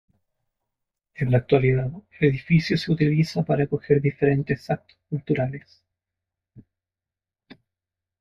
Pronounced as (IPA) /akoˈxeɾ/